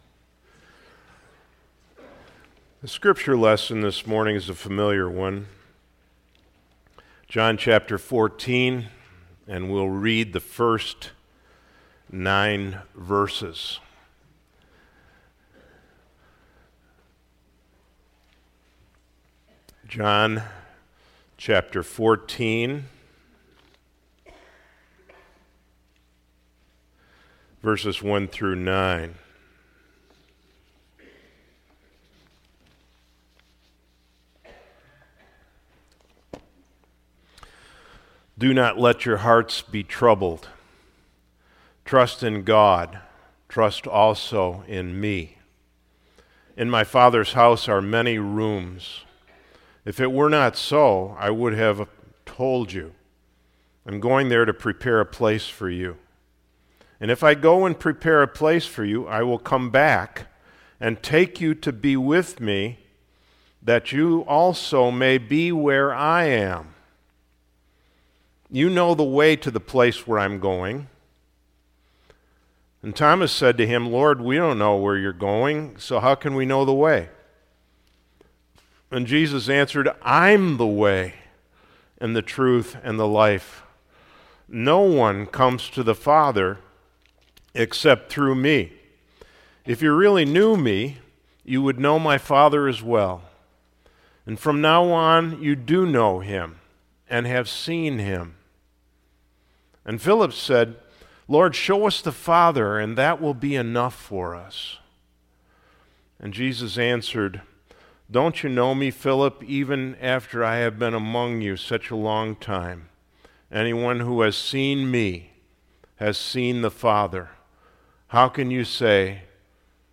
Sermons | Eighth Reformed Church